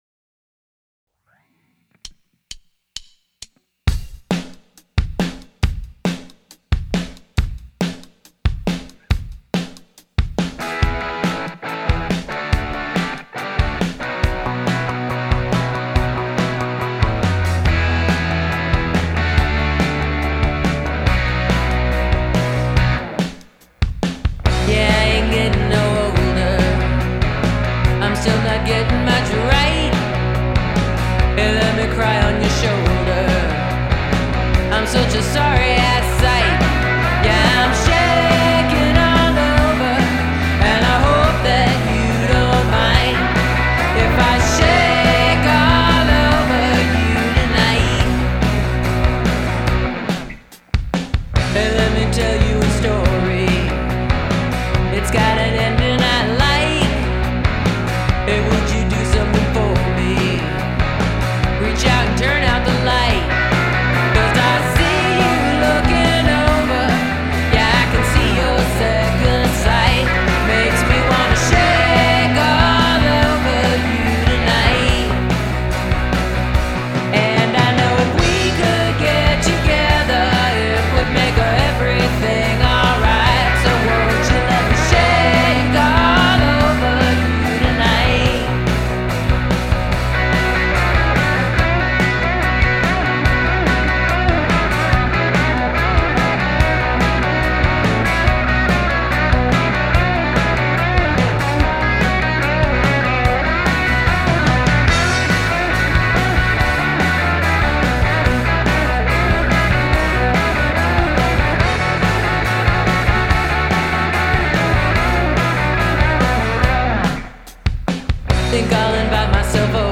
vocals and guitars
guitars and bass
drums